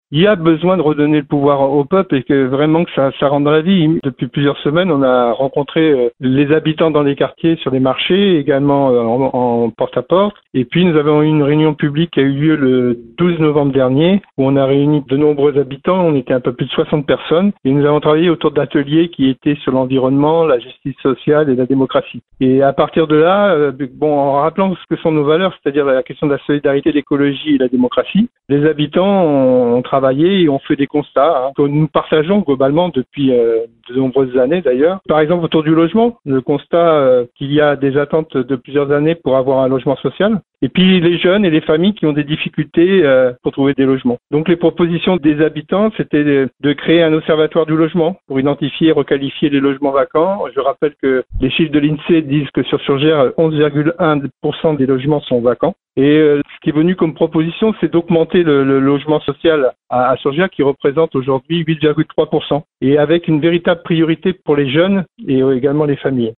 Le candidat de la liste « Surgères à gauche » a détaillé ce mardi en conférence de presse une série de six propositions citoyennes qui s’inscrivent dans un projet élaboré en co-construction avec les habitants de la ville. Car le conseiller municipal d’opposition en est convaincu : les défis de Surgères autour des questions prioritaires du logement, de la santé, de l’écologie, de la mobilité et de la jeunesse ne se résoudront qu’avec eux.